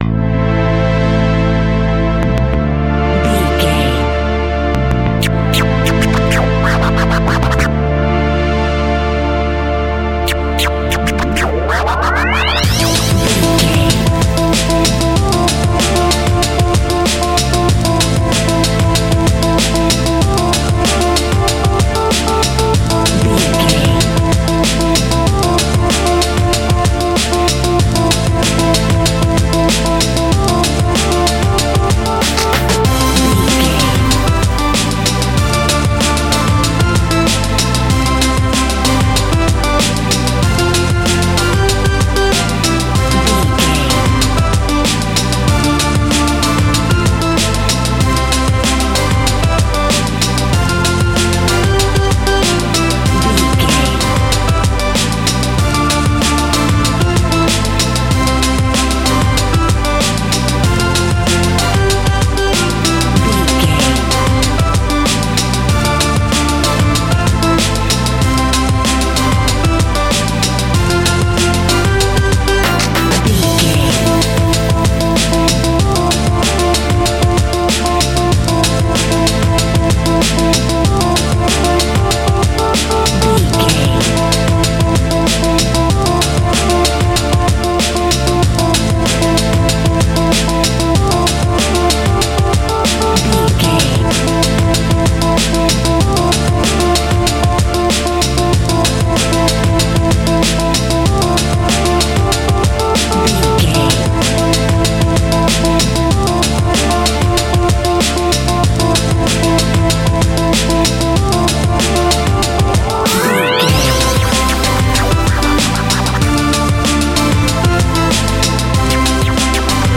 Aeolian/Minor
hip hop
hip hop instrumentals
downtempo
synth lead
synth bass
synth drums
hip hop loops